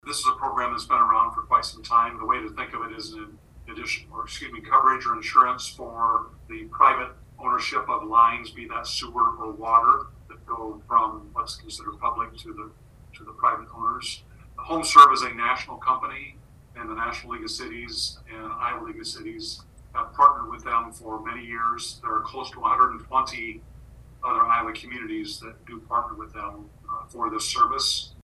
Harlan City Administrator Gene Gettys explains…